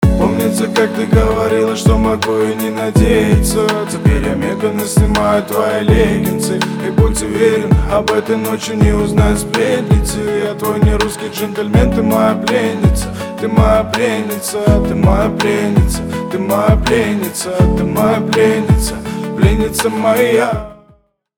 • Качество: 320 kbps, Stereo
Рэп и Хип Хоп
тихие